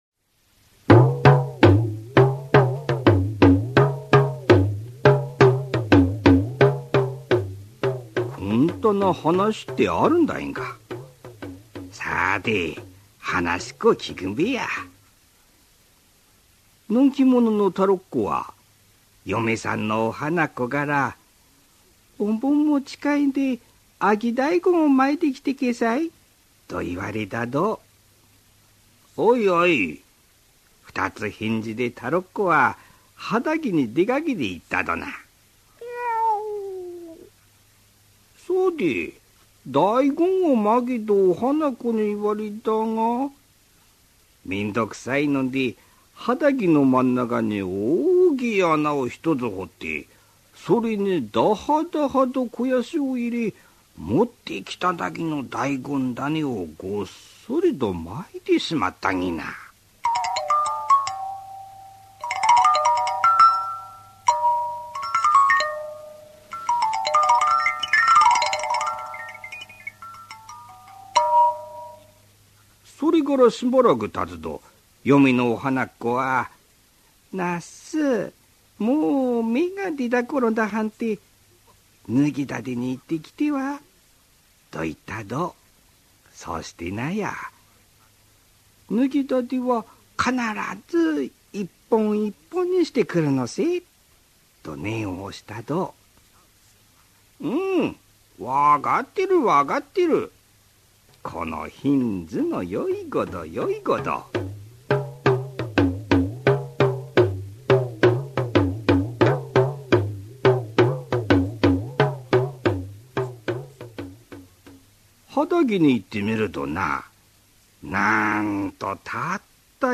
[オーディオブック] はなしみたいな話